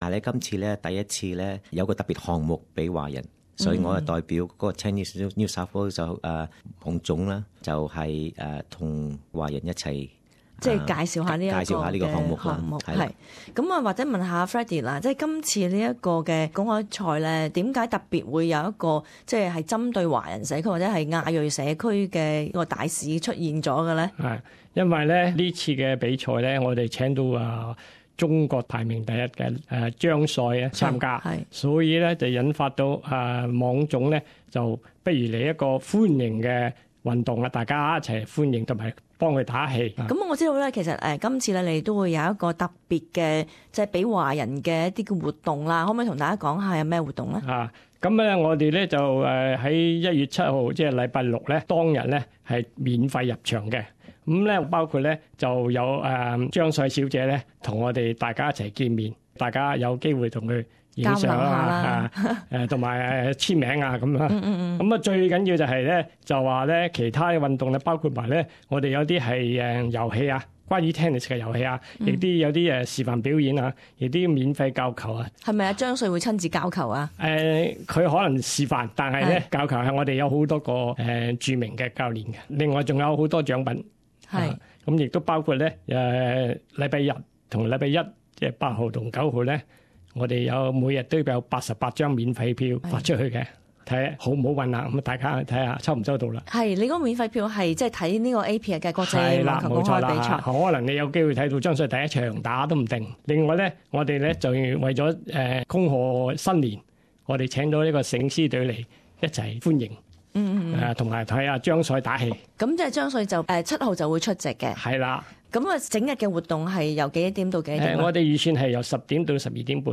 【社區訪問】免費網球活動招待華裔人士